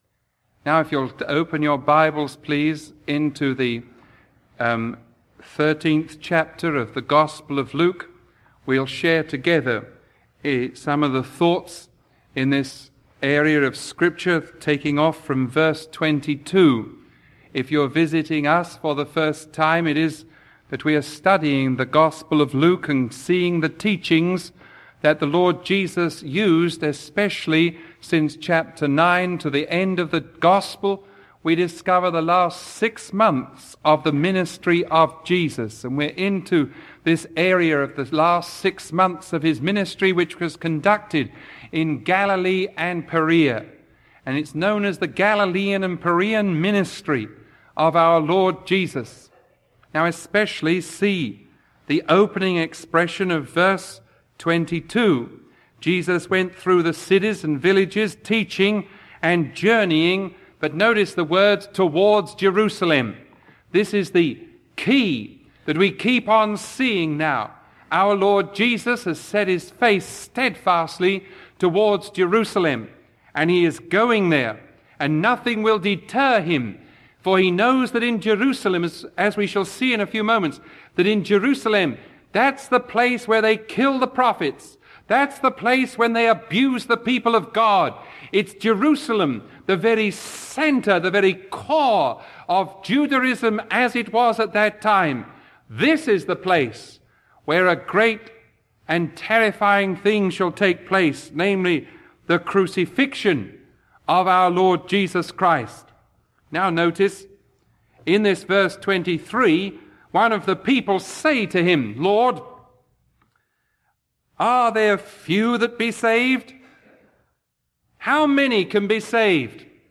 Sermon 0086A recorded on September 2